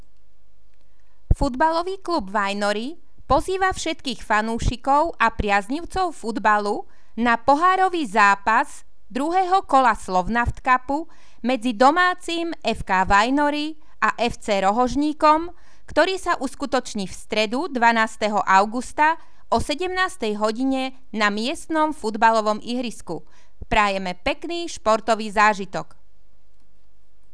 Hlásenie rozhlasu
• Hlásenie miestneho rozhlasu FK Vajnory - FC Rohožník 12.8.